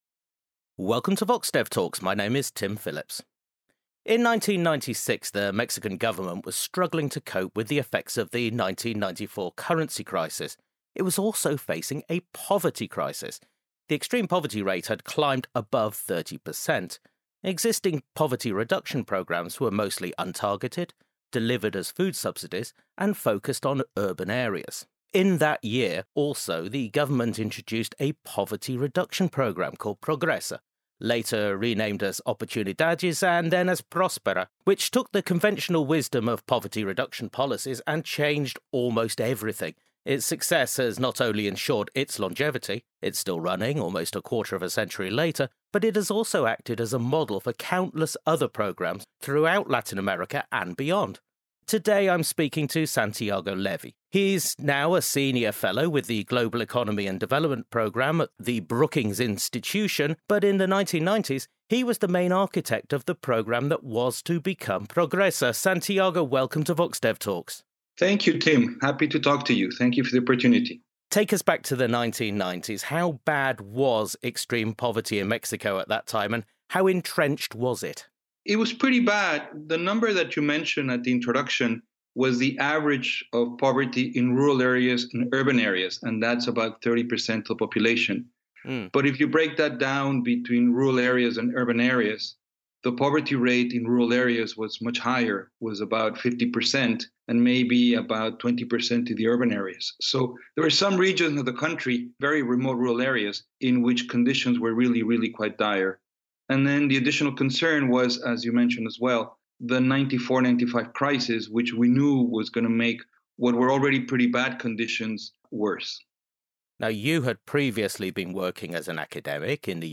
In this VoxDev talk, Santiago Levy, one of the main architects of the programme that was to become Progresa, takes us back to the 1990s to discuss the creation of the project. He also explains how it managed to avoid the traps that have prevented similar programmes in other countries from being as successful.